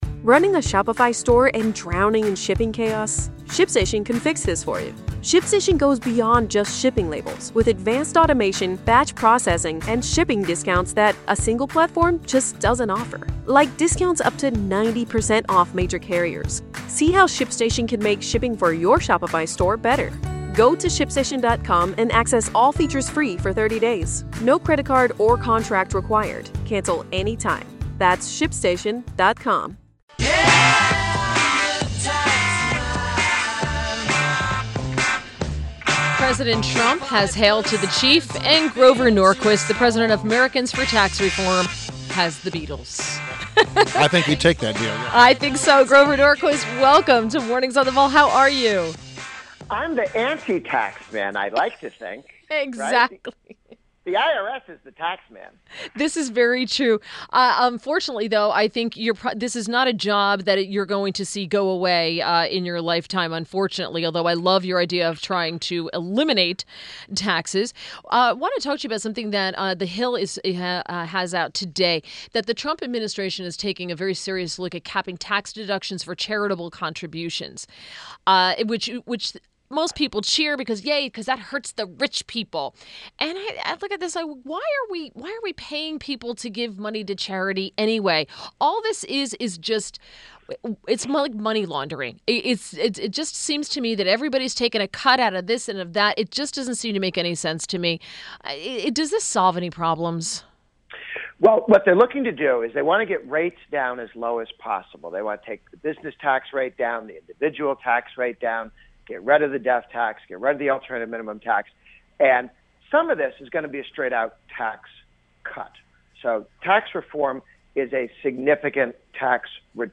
WMAL Interview - GROVER NORQUIST - 04.21.17
INTERVIEW – GROVER NORQUIST – President, Americans for Tax Reform